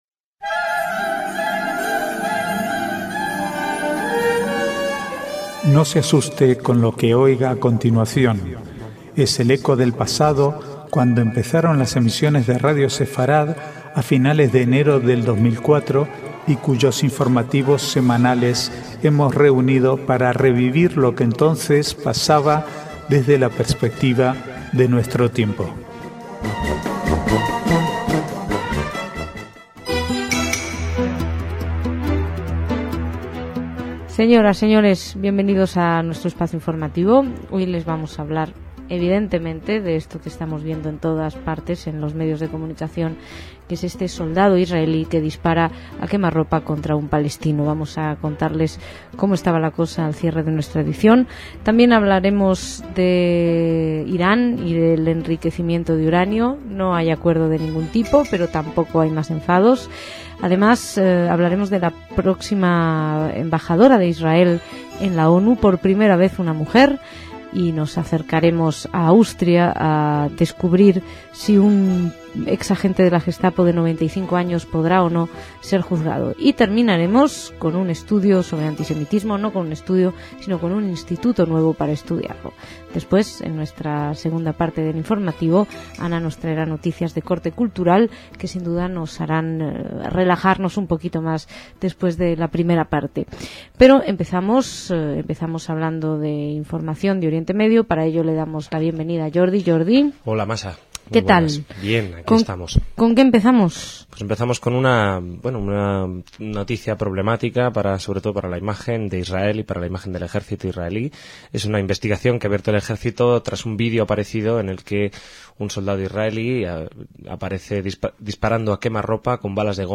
Archivo de noticias del 22 al 24/7/2008